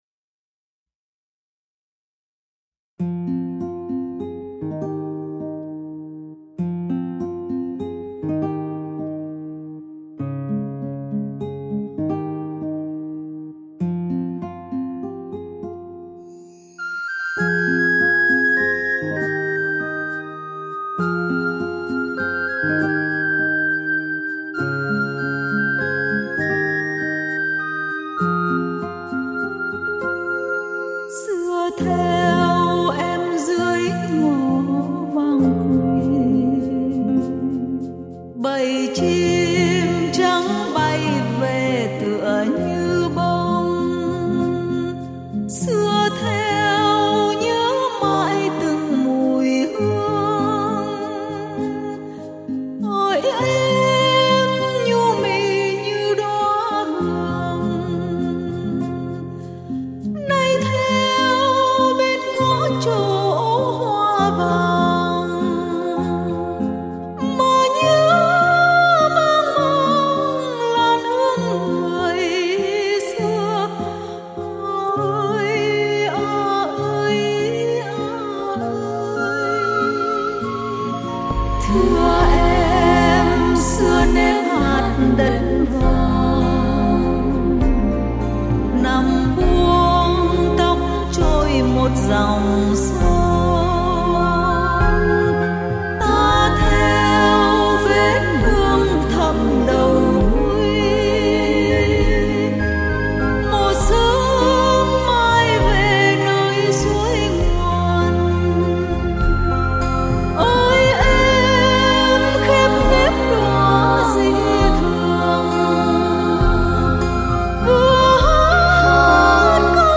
Tiếng hát